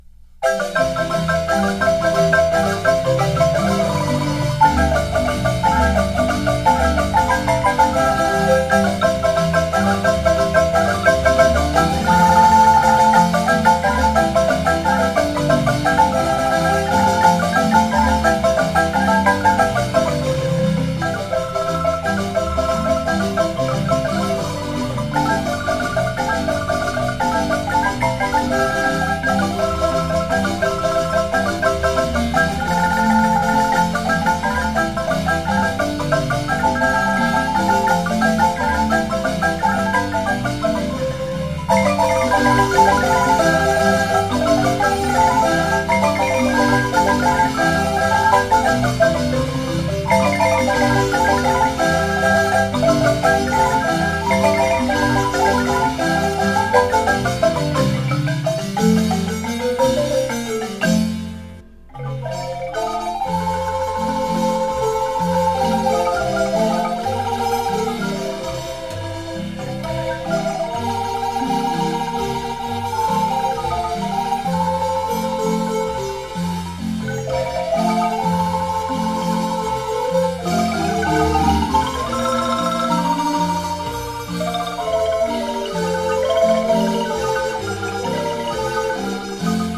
parrandera
Música guanacasteca: marimba